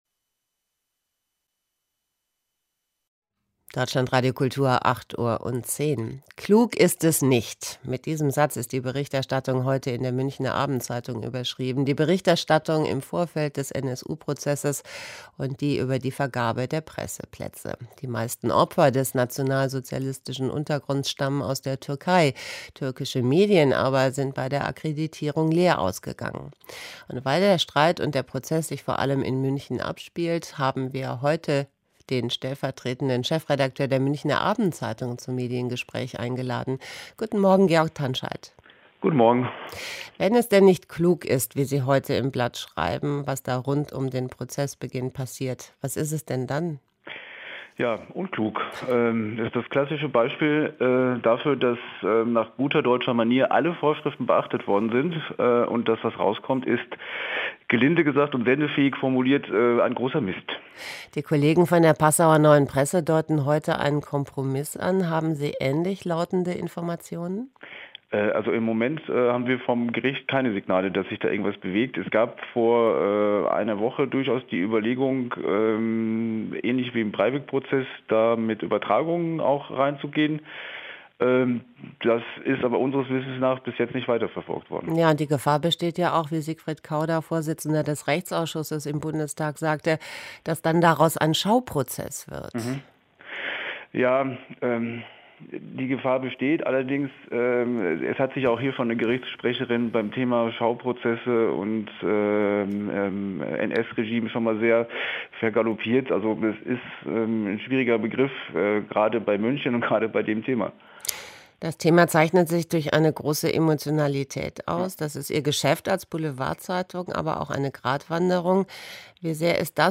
Audio-File: Das komplette Interview